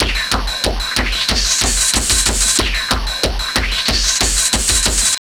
SWEEP STUFF 2.wav